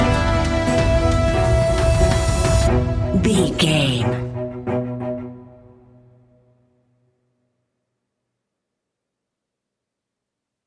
In-crescendo
Thriller
Aeolian/Minor
ominous
dark
eerie
strings
percussion
piano
ticking
electronic music